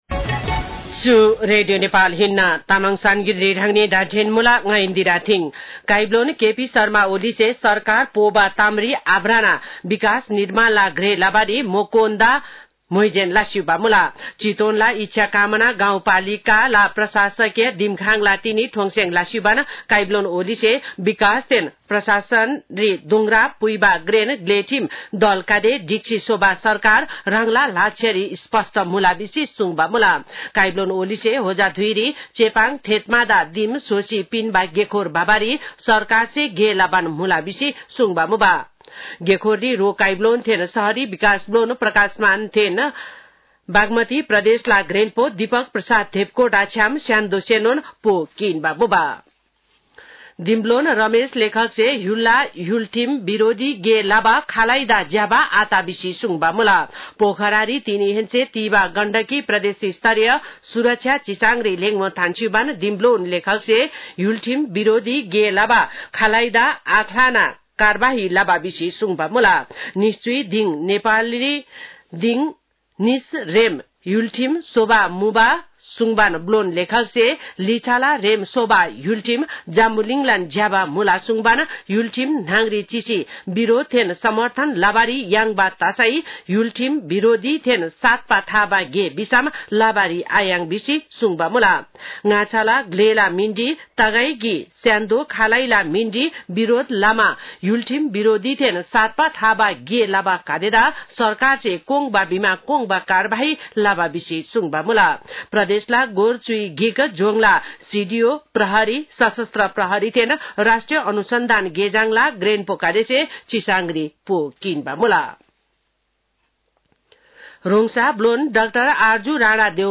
तामाङ भाषाको समाचार : ५ वैशाख , २०८२
Tamang-news-1-05.mp3